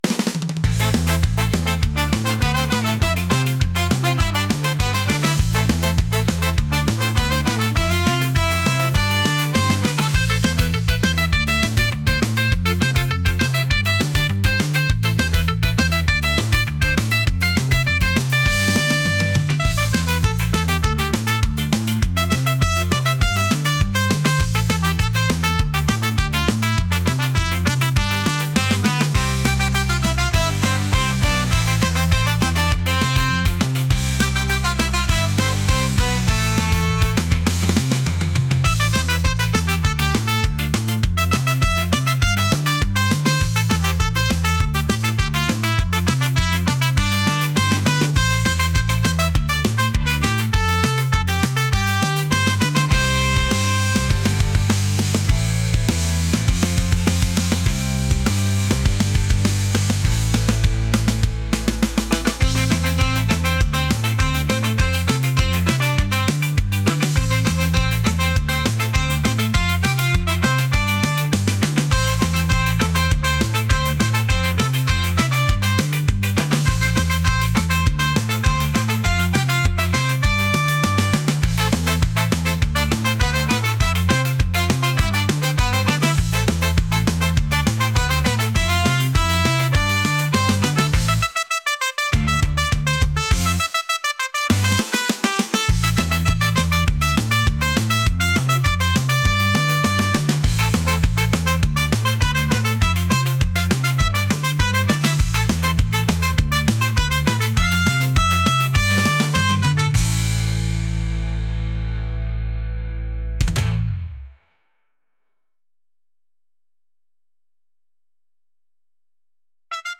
ska | punk | upbeat | energetic